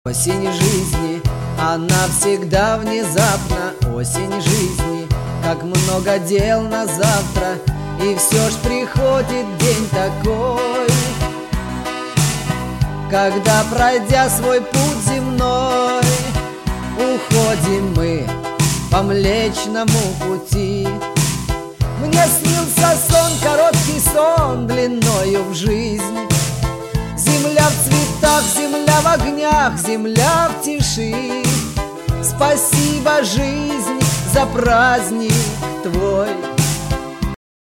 • Качество: 128, Stereo
русский шансон, городской романс